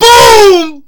Boom4.ogg